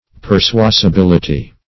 Search Result for " persuasibility" : The Collaborative International Dictionary of English v.0.48: Persuasibility \Per*sua`si*bil"i*ty\, n. Capability of being persuaded.